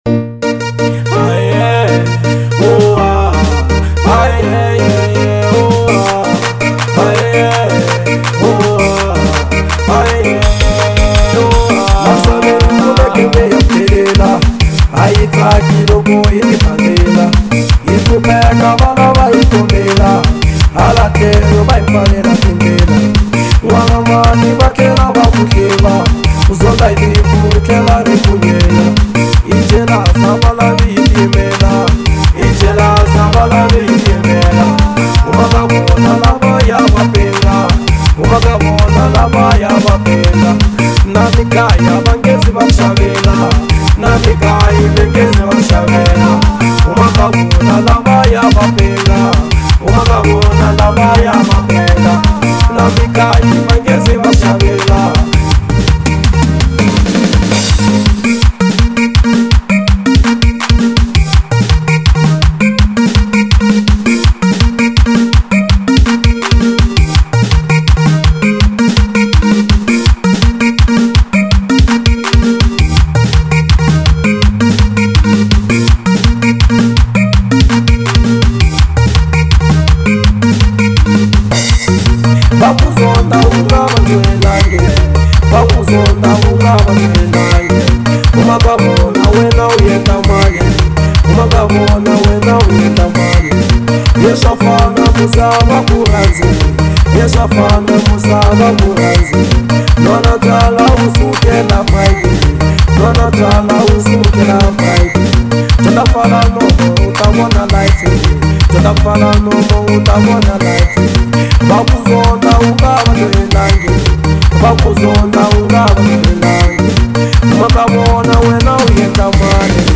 04:00 Genre : Xitsonga Size